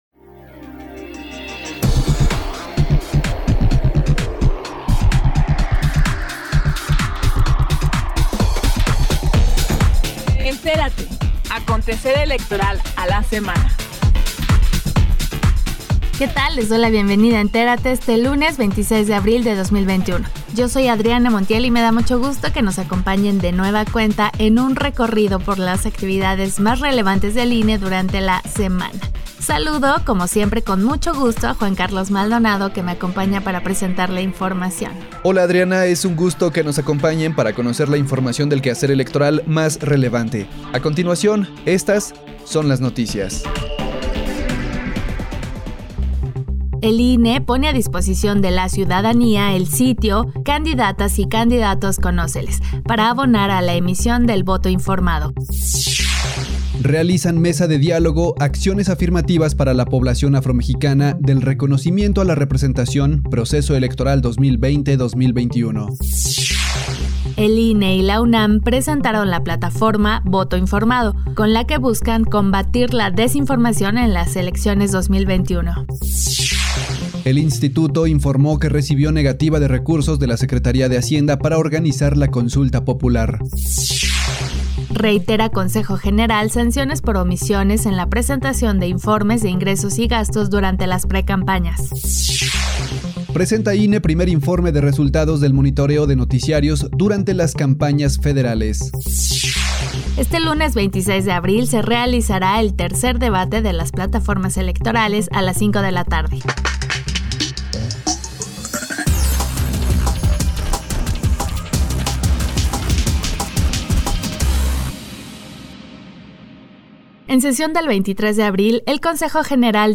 NOTICIARIO 26 DE ABRIL 2021